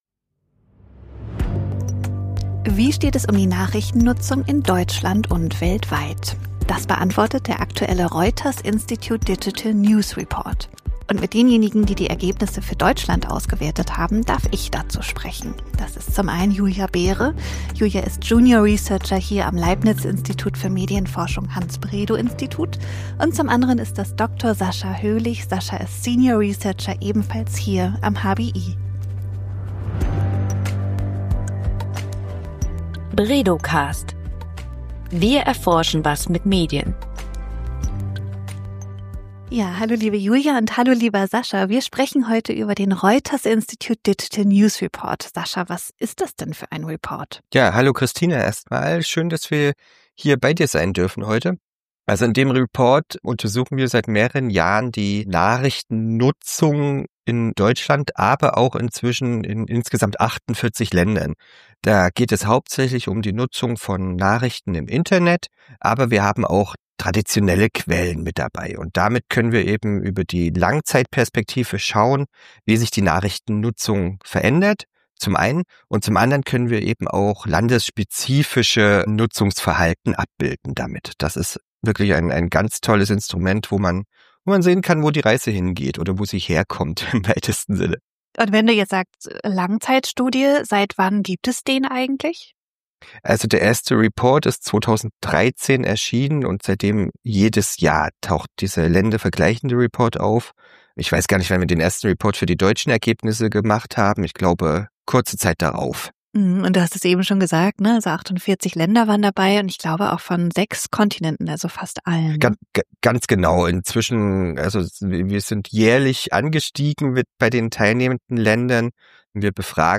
Im Podcast sprechen die beiden Medienforschenden über zentrale Ergebnisse der aktuellen Erhebung und ordnen sie ein.